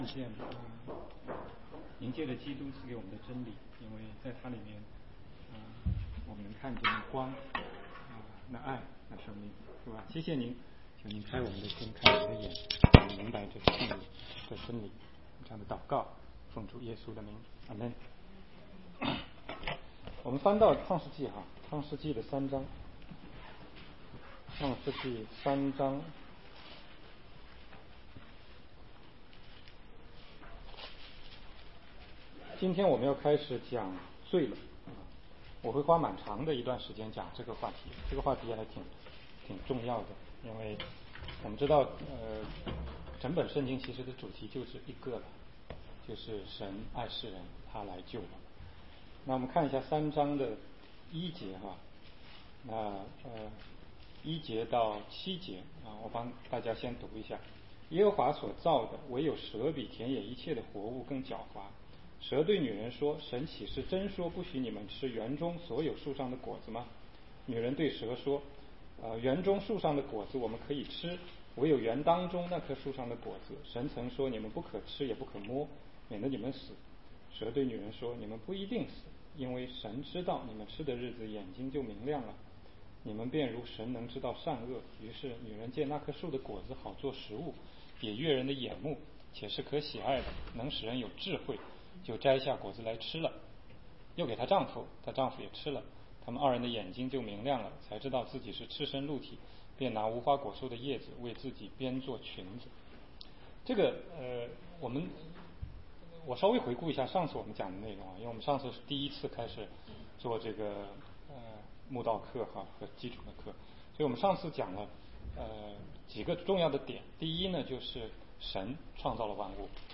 16街讲道录音 - 亚当夏娃的原罪